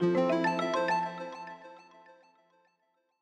Longhorn 8 - Notify Calendar.wav